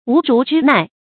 無如之奈 注音： ㄨˊ ㄖㄨˊ ㄓㄧ ㄣㄞˋ 讀音讀法： 意思解釋： 猶無如之何。